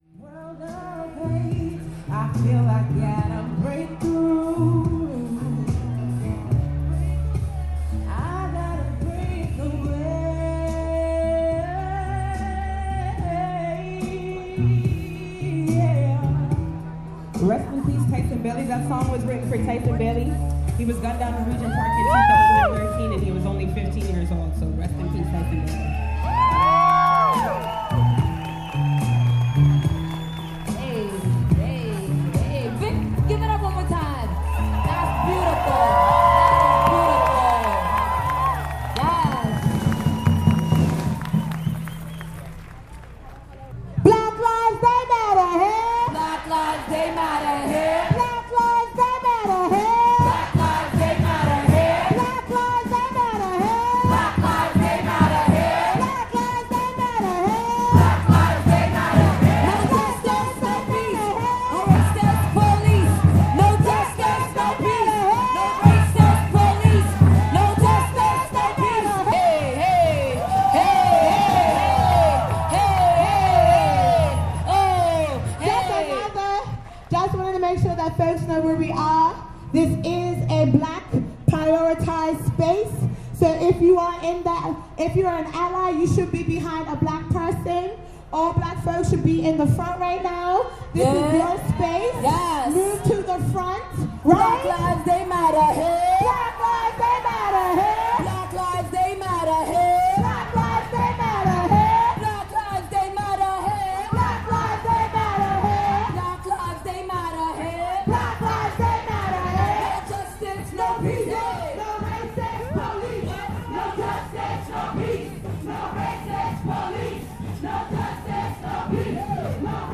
Sound collage from the March 26, 2016 "Blackout Against Police Brutality" in Toronto
Recording Location: Toronto
Credits: Black Lives Matter - Toronto organized this event which features artists and family members or friends of people of Black people killed by police.
Type: Actuality